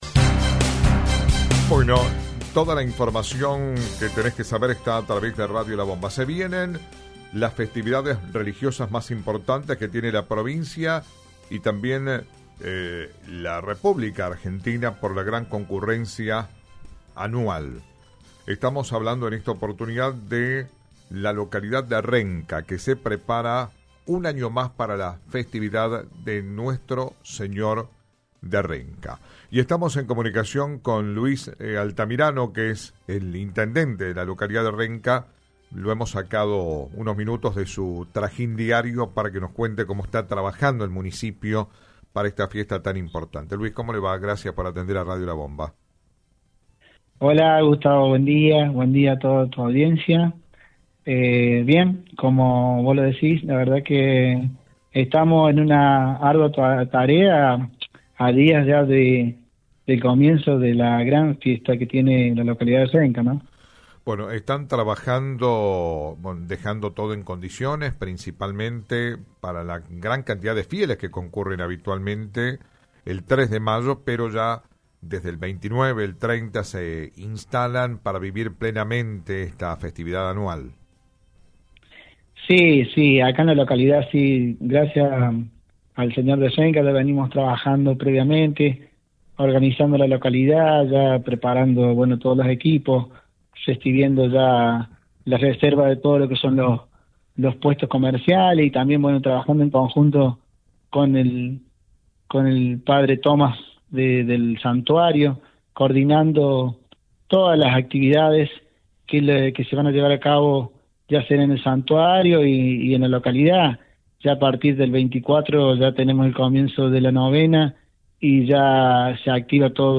entrevistaron al intendente de Renca, Luis Altamirano, sobre los preparativos para la festividad del Señor de Renca